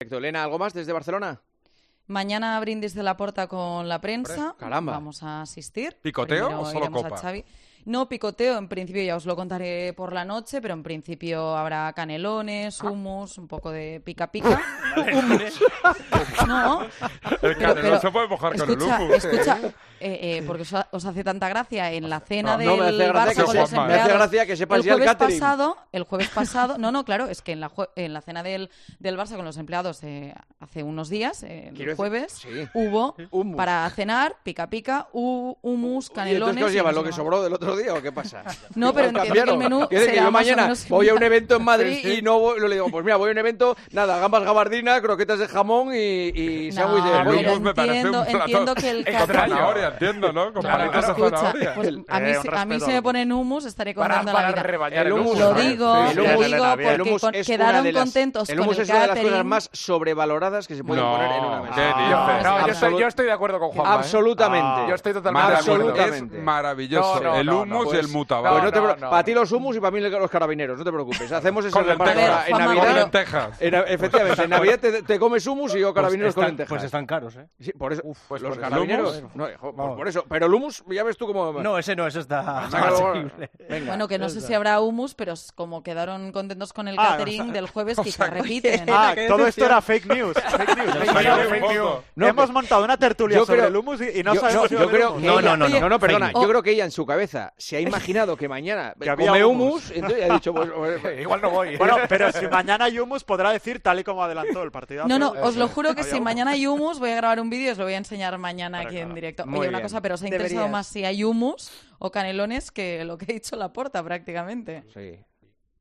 Los clubes de fútbol están ofreciendo en los últimos días también sus propios convites para la prensa y el del FC Barcelona, provocó esta conversación en El Partidazo de COPE.